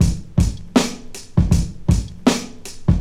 • 80 Bpm HQ Drum Loop Sample E Key.wav
Free drum loop - kick tuned to the E note. Loudest frequency: 1102Hz
80-bpm-hq-drum-loop-sample-e-key-cti.wav